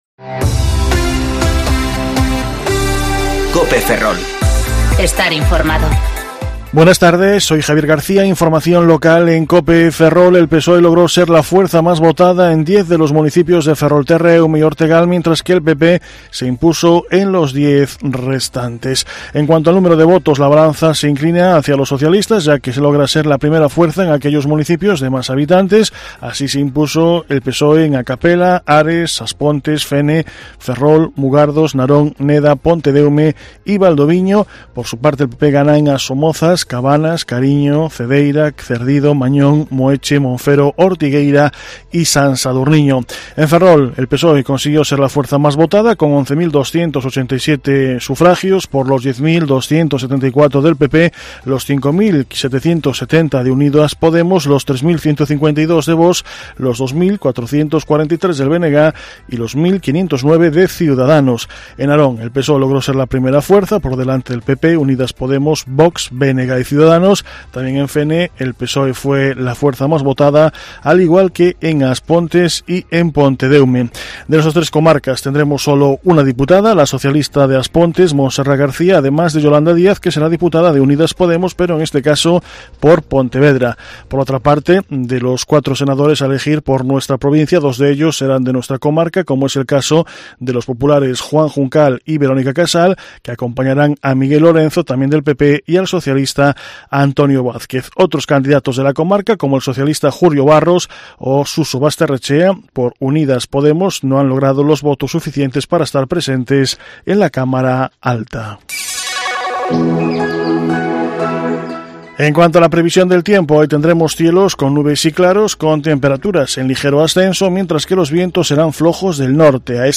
Informativo Mediodía Cope Ferrol 11/11/2019 (De 14.20 a 14.30 horas)